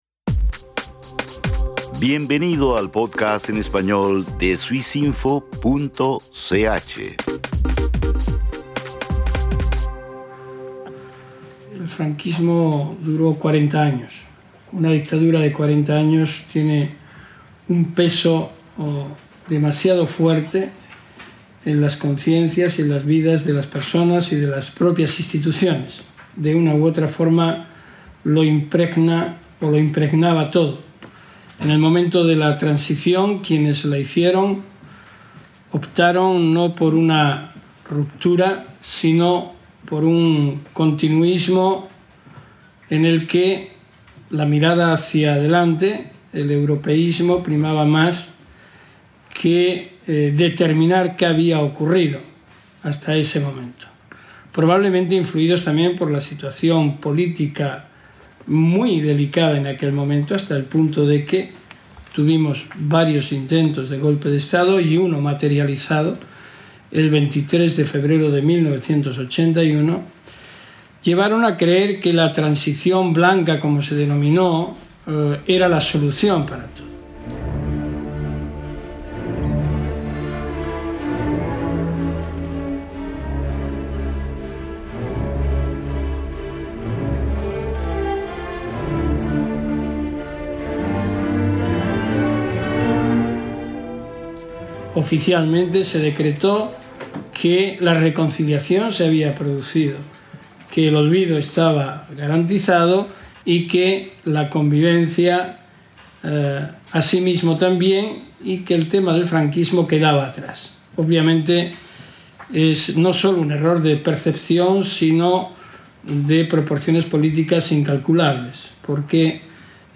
Baltasar Garzón denuncia el olvido en que se encuentran las víctimas del franquismo y la obstinación de diversos sectores a negar los horrores de la dictadura. Extractos de la conferencia de prensa el 4.11.13 en Ginebra.